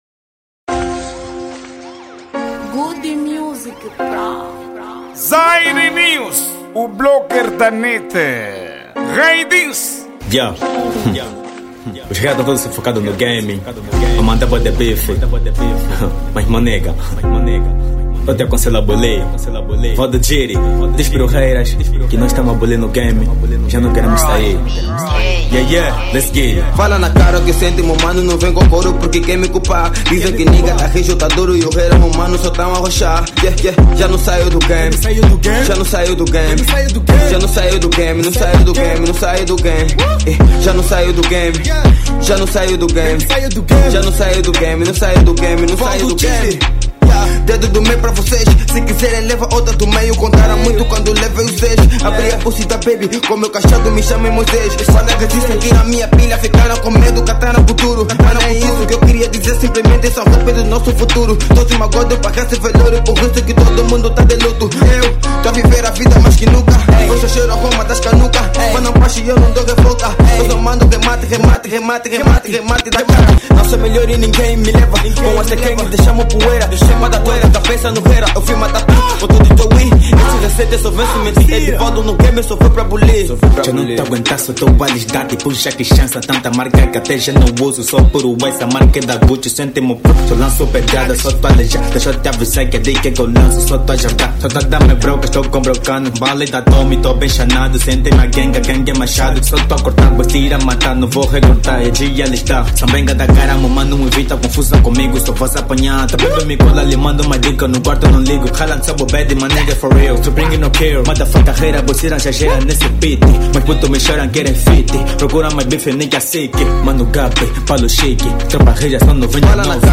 Gênero:Rap